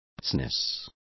Complete with pronunciation of the translation of baseness.